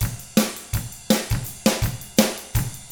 164ROCK F4-L.wav